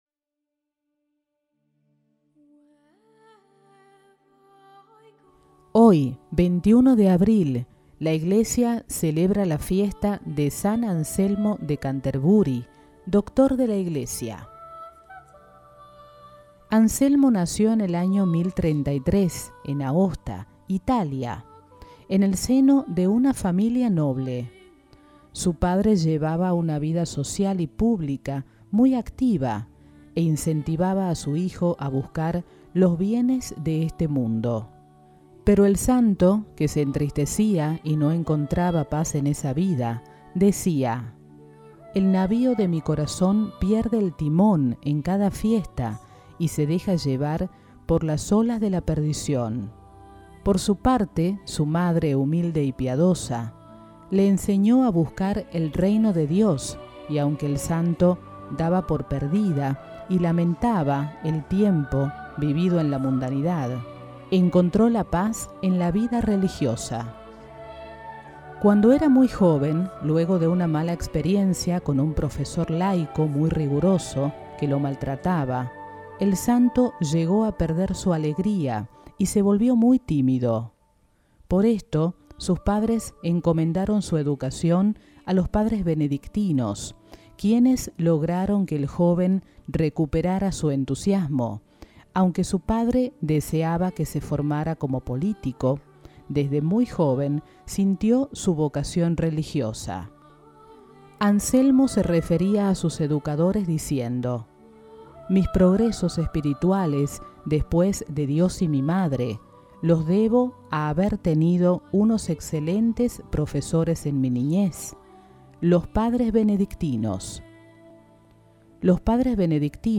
San-Anselmo-de-Canterbury-con-musica.mp3